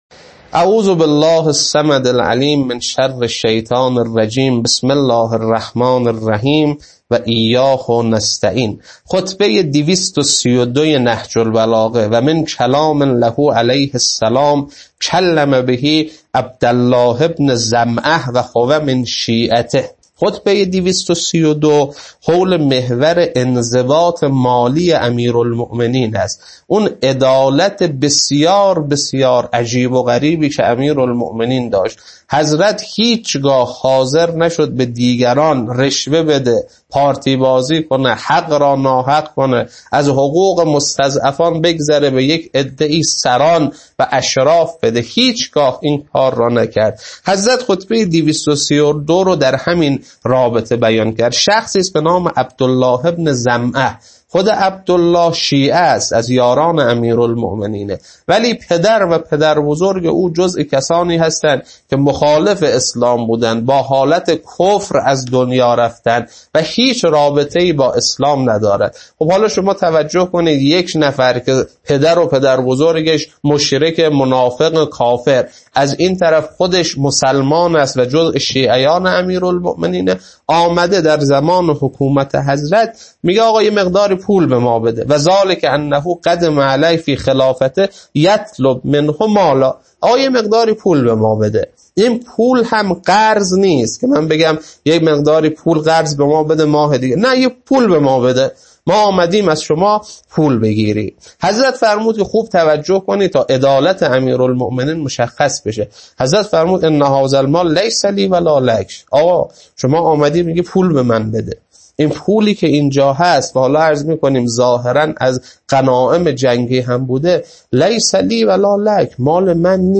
خطبه 232.mp3
خطبه-232.mp3